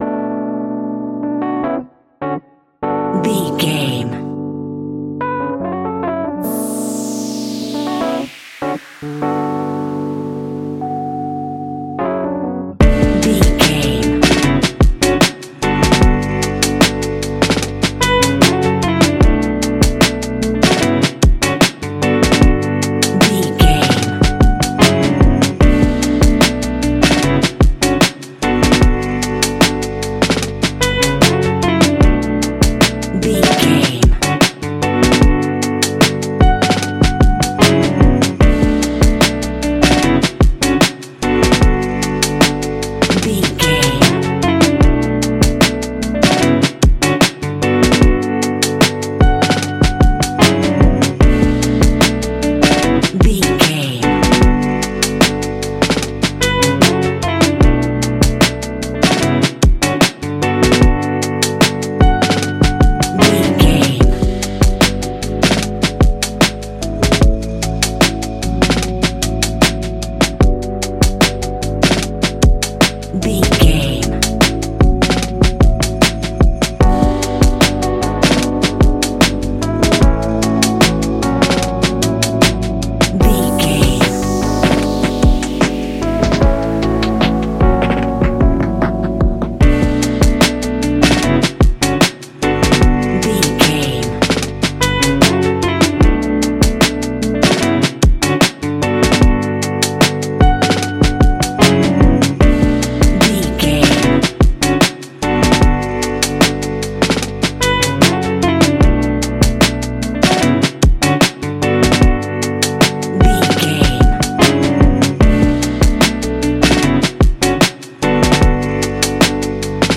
Ionian/Major
D♭
laid back
Lounge
sparse
new age
chilled electronica
ambient
atmospheric
instrumentals